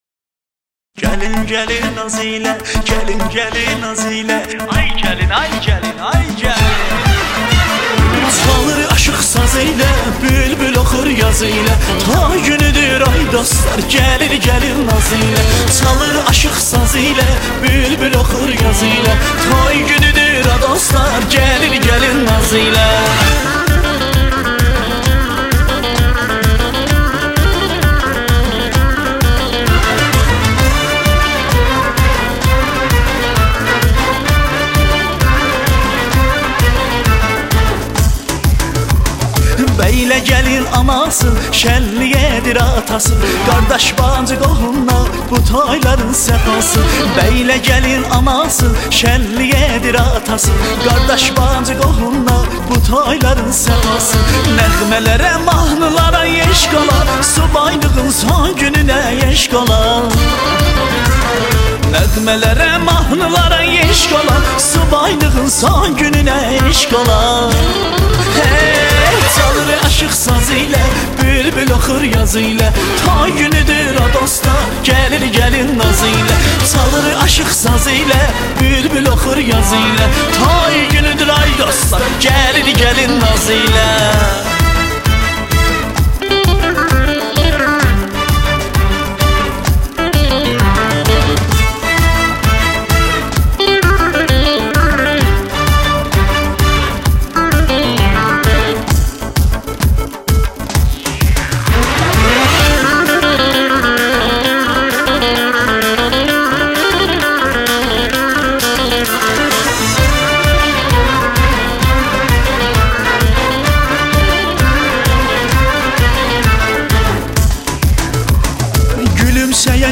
آهنگ شاد رقصیدنی ترکی ارکستی برای رقص عروس داماد